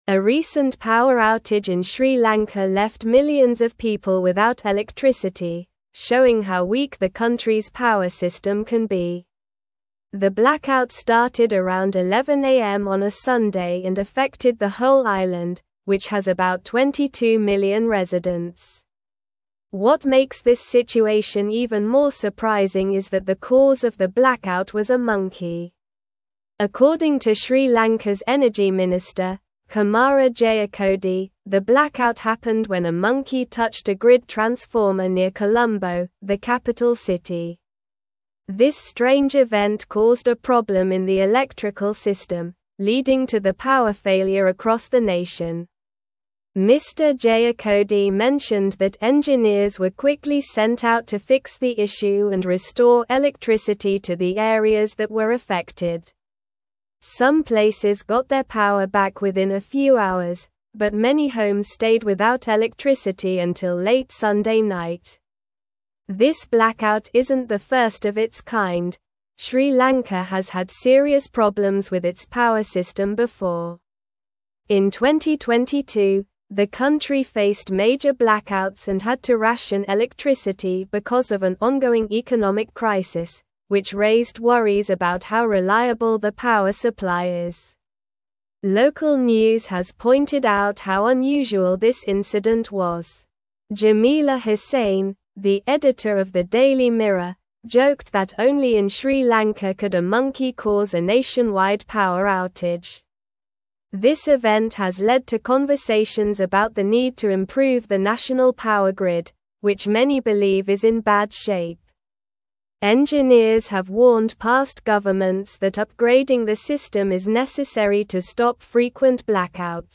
Slow
English-Newsroom-Upper-Intermediate-SLOW-Reading-Monkey-Causes-Nationwide-Blackout-in-Sri-Lanka.mp3